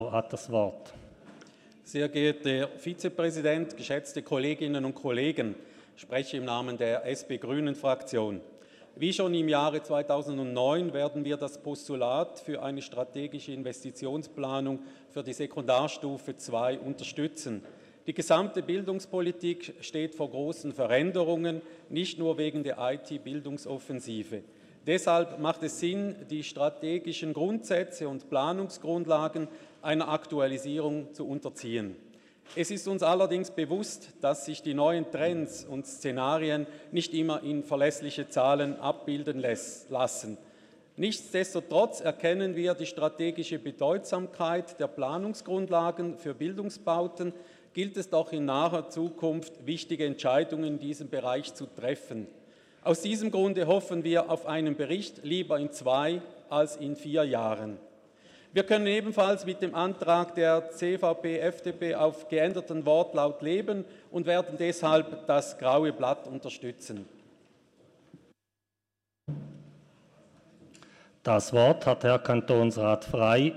(im Namen der SP-GRÜ-Fraktion):
Session des Kantonsrates vom 11. bis 13. Juni 2019